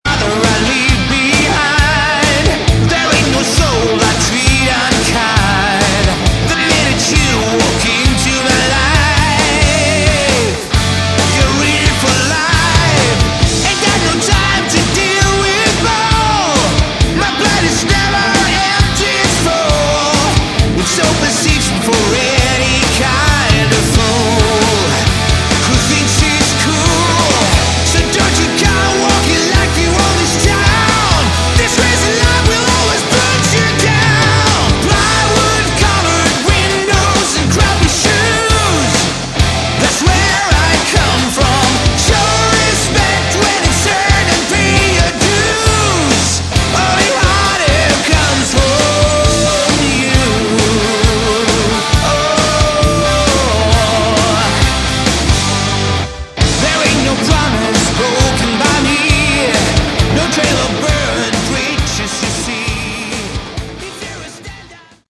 Category: Hard Rock
vocals